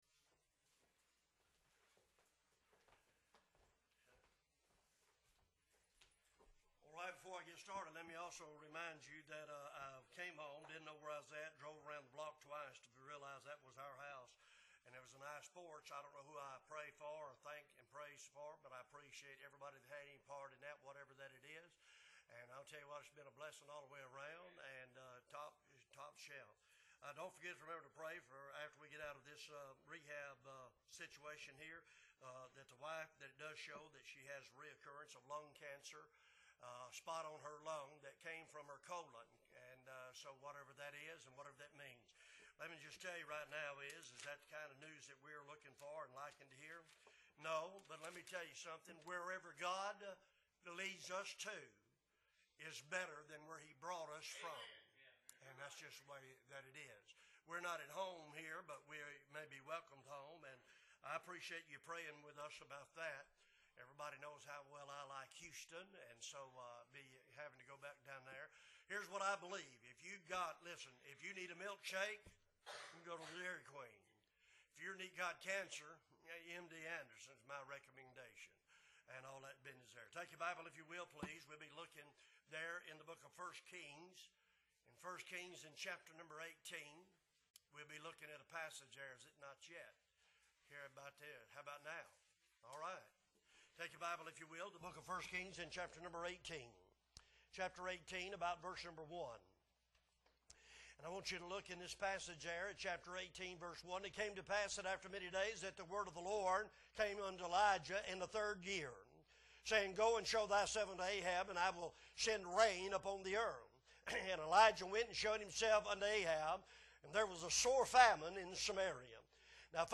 October 22, 2023 Sunday Morning Services - Appleby Baptist Church